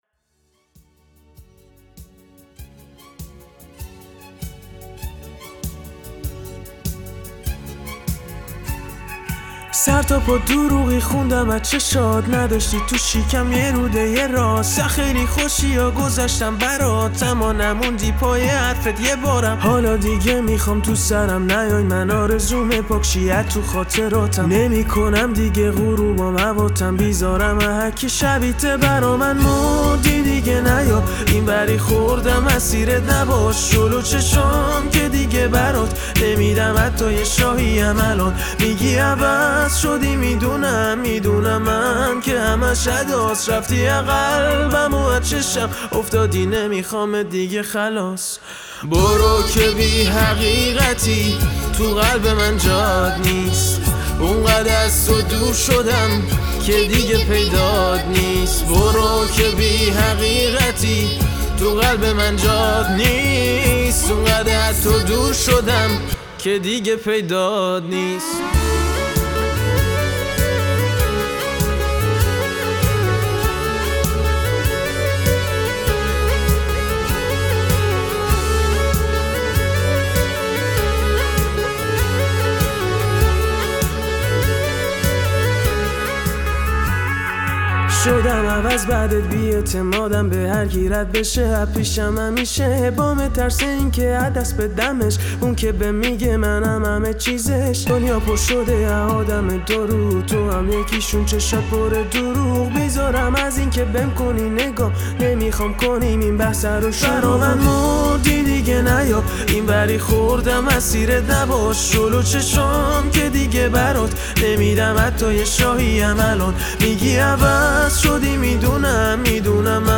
دانلود آهنگ شاد جدید